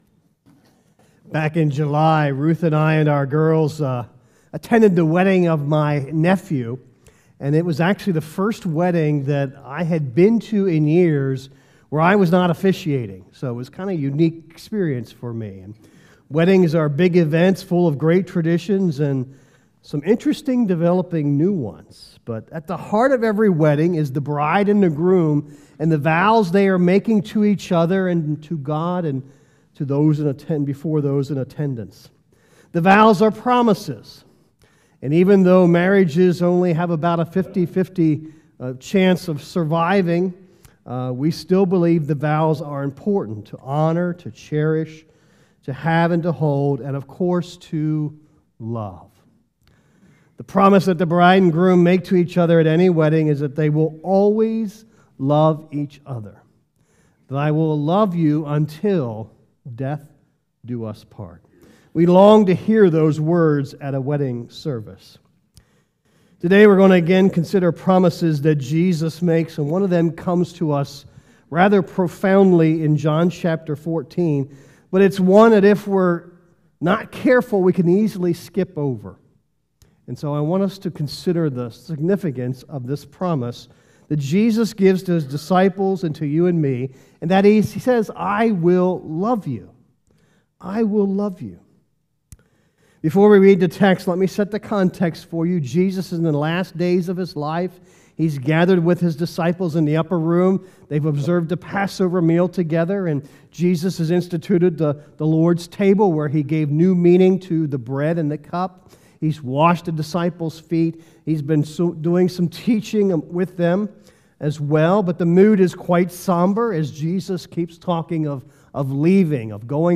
Sermons | Mechanic Grove Church of the Brethren